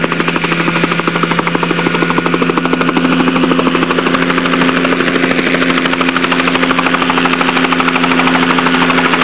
Chopper
chopper.wav